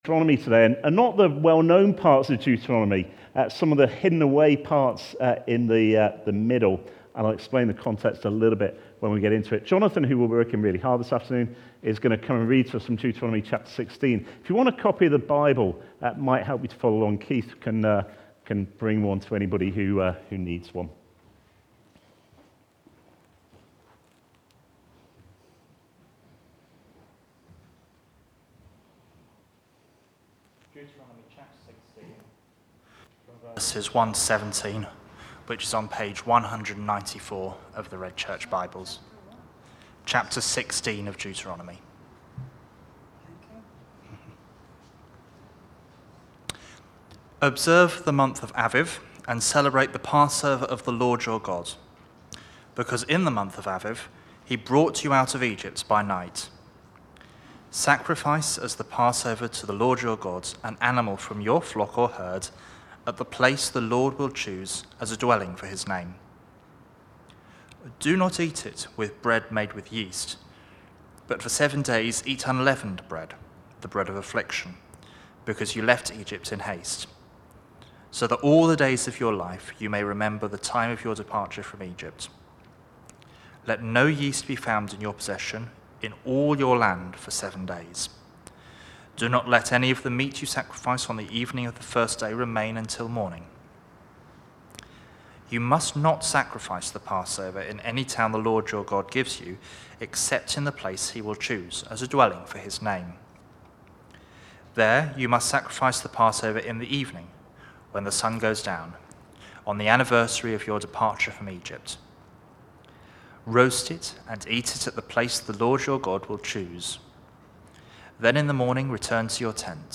Leadership (Deuteronomy 16:1-17) from the series Wilderness Wanderings. Recorded at Woodstock Road Baptist Church on 23 October 2022.